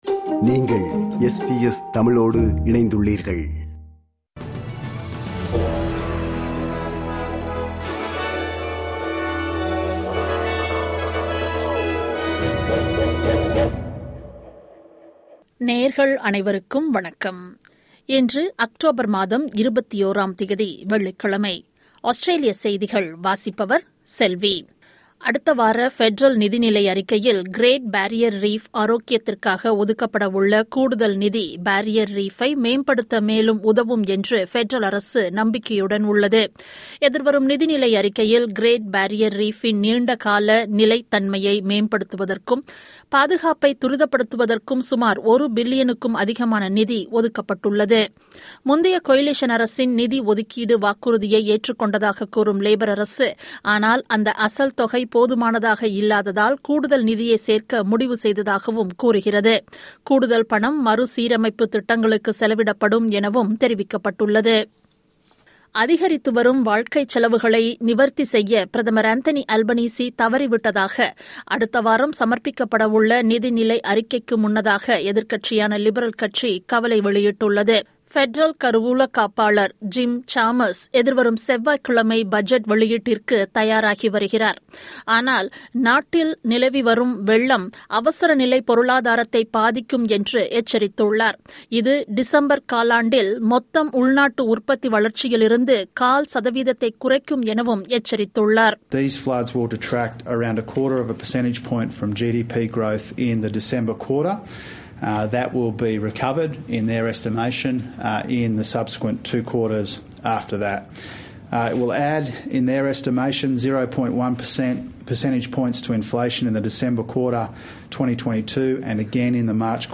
Australian news bulletin for Friday 21 October 2022.